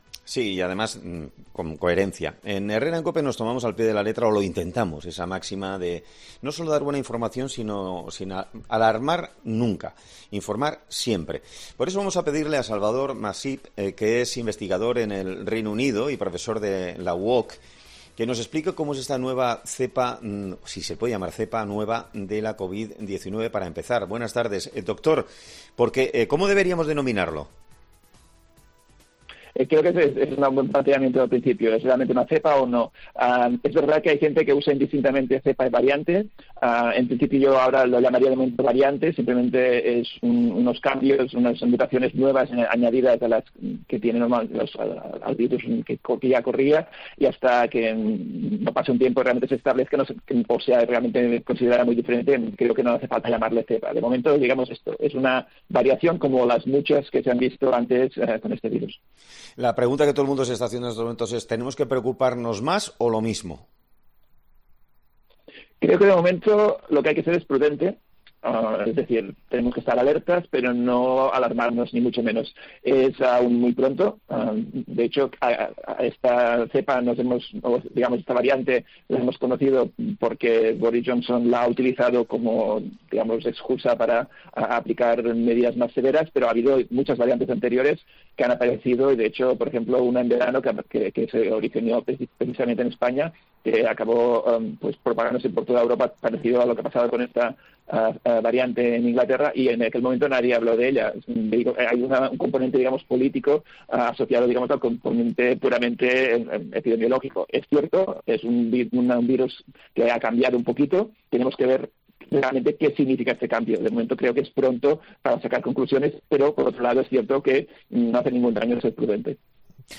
este lunes ha sido entrevistado en 'Herrera en COPE'